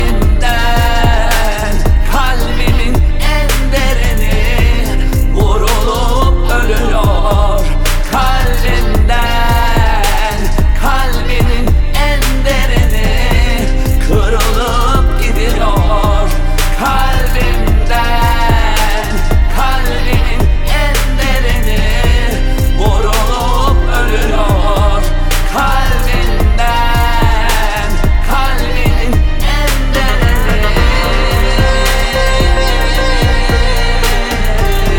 Жанр: Иностранный рок / Рок / Инди / Русские
# Indie Rock